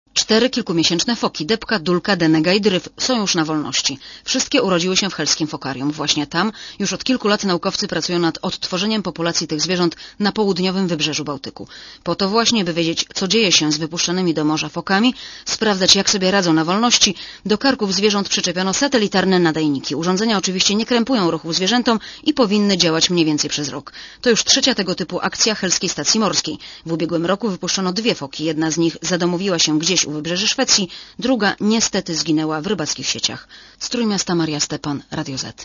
reporterki Radia ZET